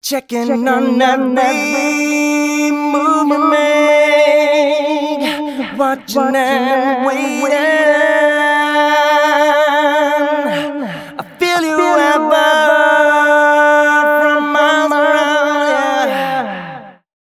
006 male.wav